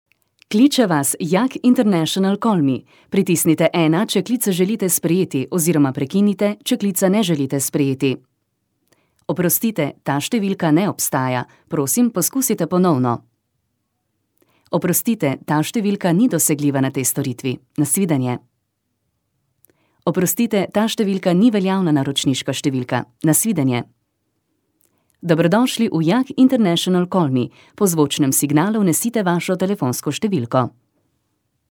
Professionelle slowenische Sprecherin (Muttersprachlerin) für TV / Rundfunk / Industrie / Werbung.
Sprecherin slowenisch (Muttersprachlerin) für TV / Rundfunk / Industrie / Werbung.
Sprechprobe: Werbung (Muttersprache):